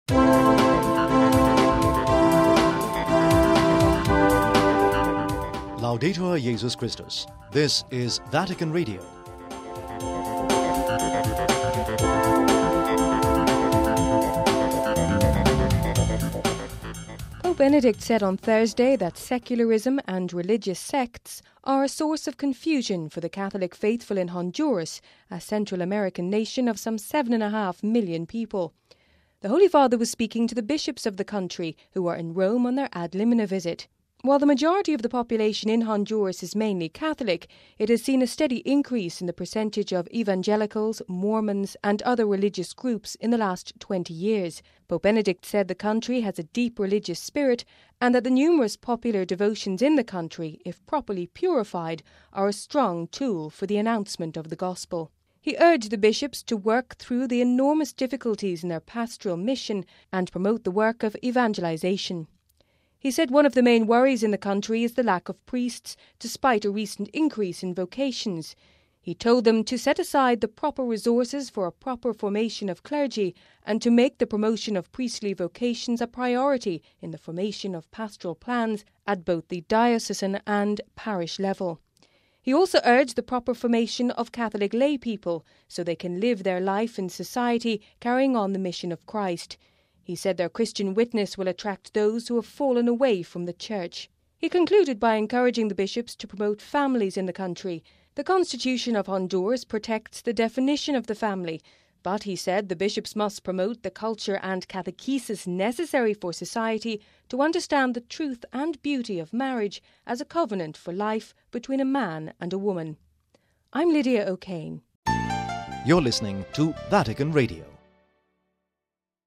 We have this report...